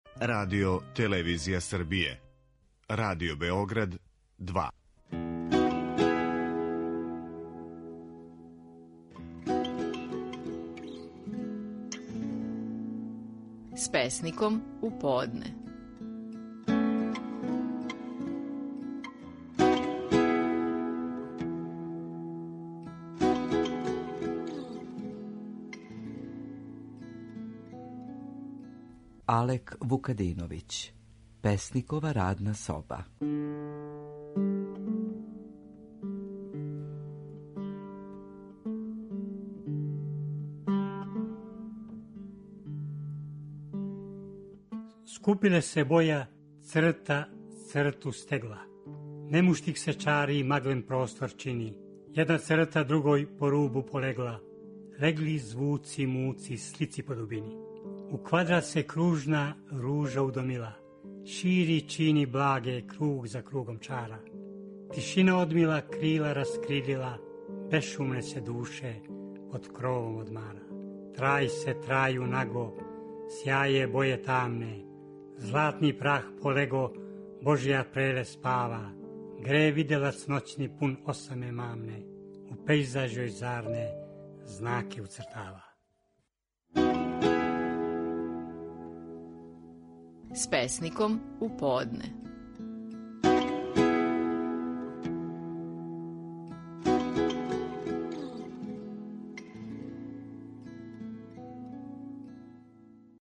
Стихови наших најпознатијих песника, у интерпретацији аутора.
Алек Вукадиновић говори стихове под насловом „Песникова радна соба".